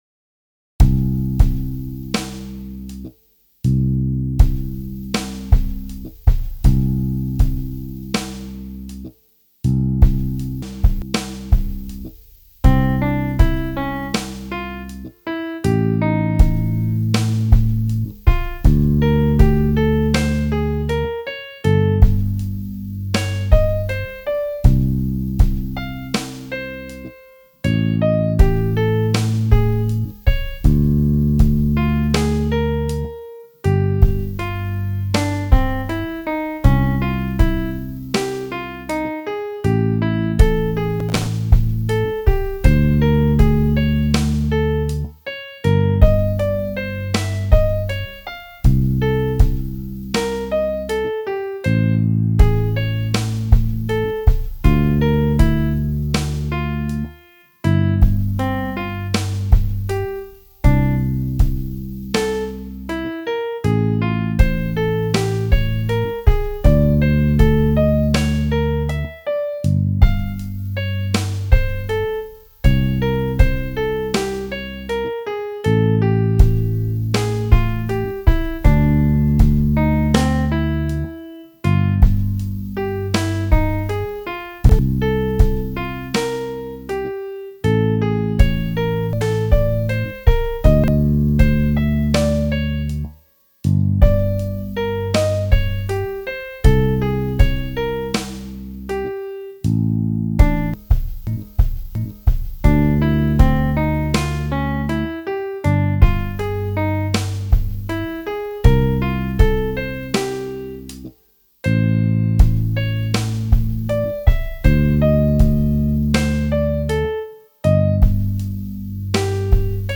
This étude explores side stepping using sixteenth notes.
C Major Pentatonic Slow Tempo